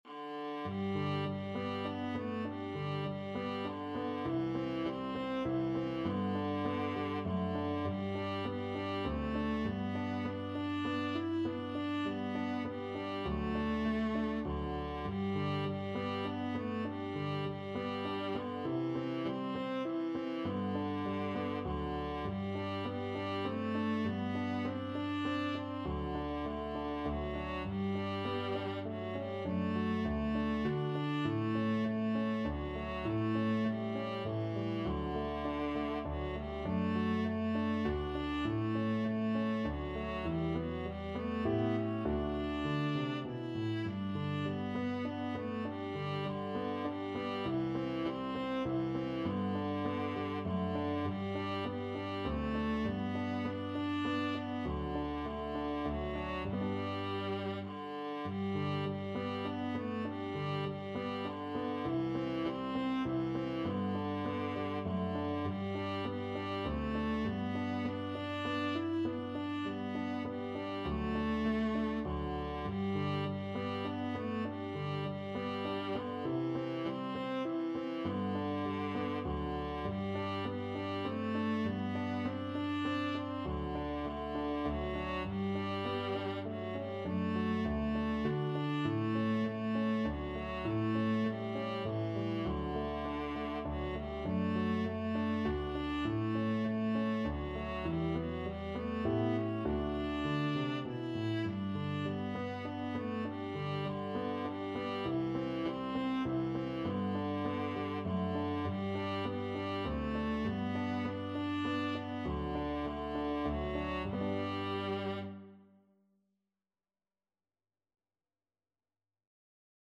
Viola
Traditional Music of unknown author.
G major (Sounding Pitch) (View more G major Music for Viola )
Moderato
3/4 (View more 3/4 Music)
Classical (View more Classical Viola Music)